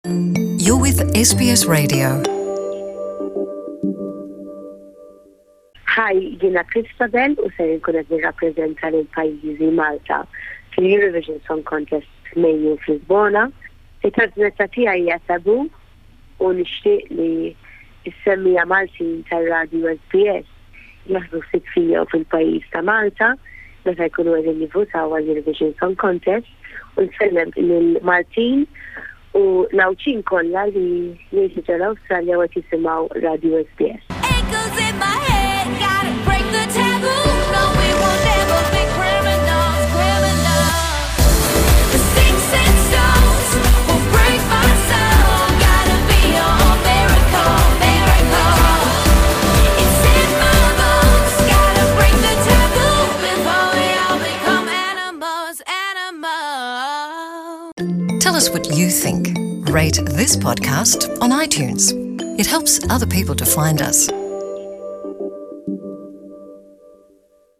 It-tieni prova ta’ Christabelle Borg fuq il-palk tal-Eurovision 2018